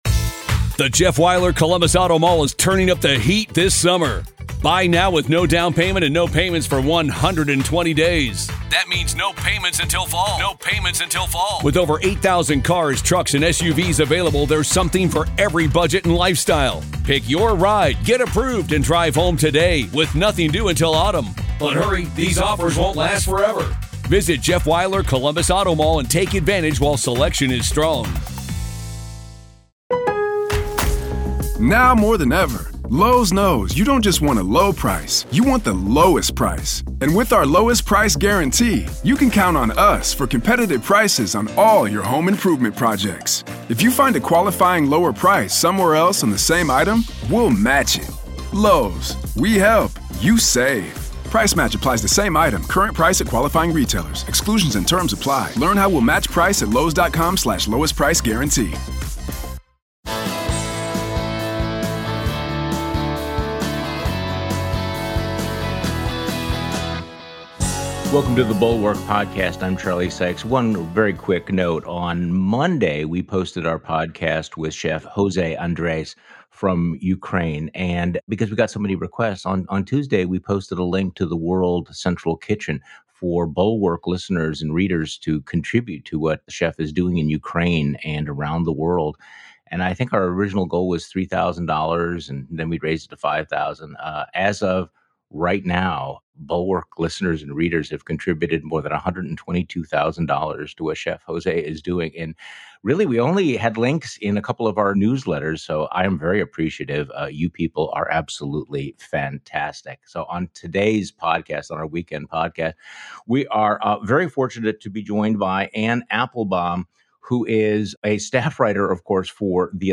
Russian media is deliberately undermining the truth, which helps explain the inhuman behavior of its troops in Ukraine. Meanwhile, Zelensky is the anti-Putin Everyman who's changing the image of Ukraine in the eyes of the world. Anne Applebaum joins Charlie Sykes on the weekend podcast.